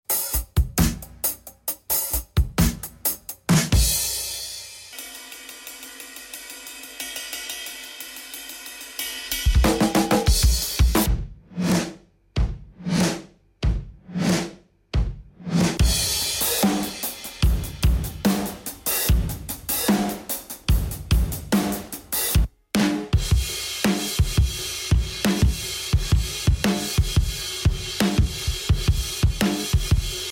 I started making these videos a few months ago as a way to learn more about how to get classic drum sounds.